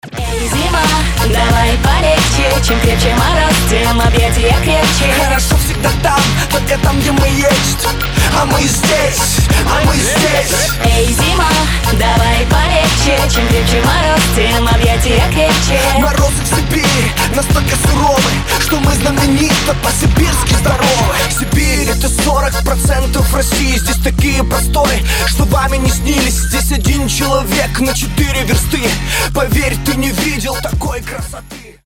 • Качество: 320, Stereo
позитивные
мужской вокал
женский вокал
Хип-хоп
из рекламы